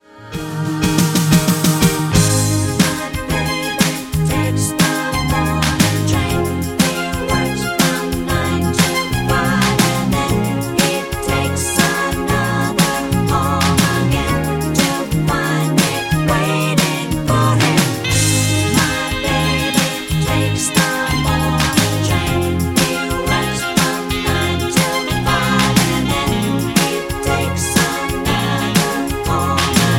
Backing track Karaoke
Pop, 1980s